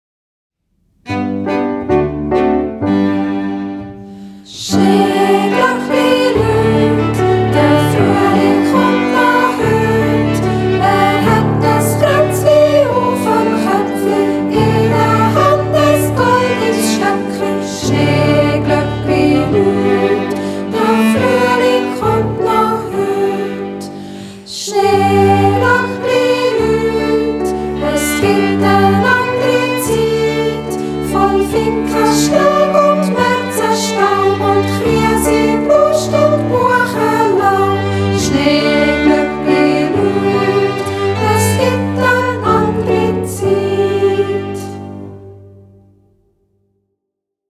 Auswahl traditioneller Lieder zum Mitsingen: